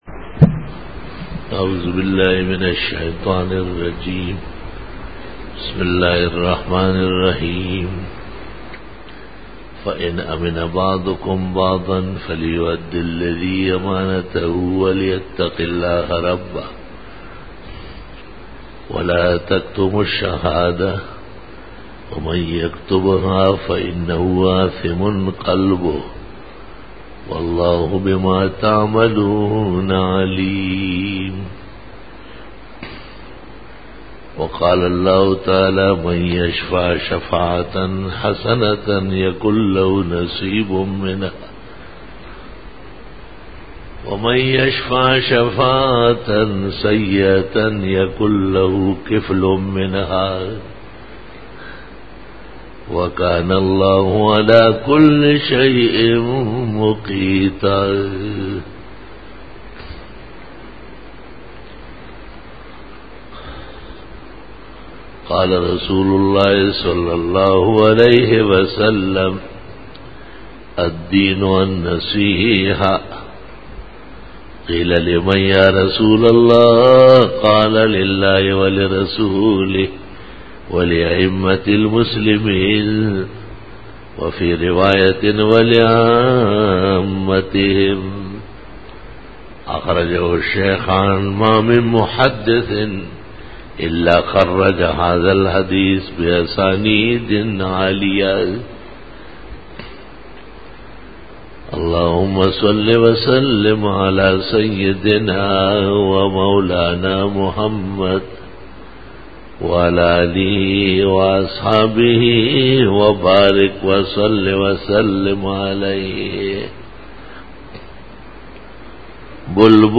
13 Bayan e juma tul mubarak 29-March-2013